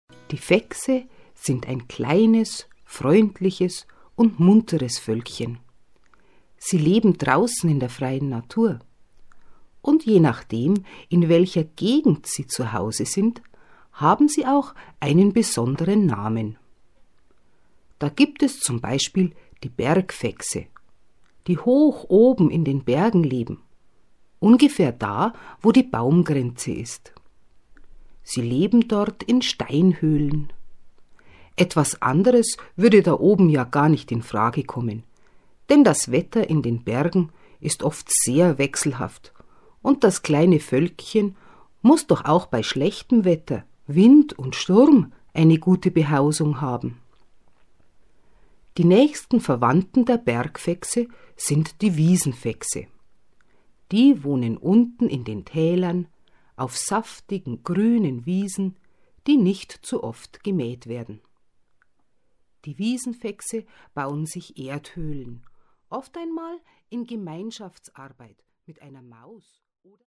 Hörspielgeschichte für kleine und große Kinder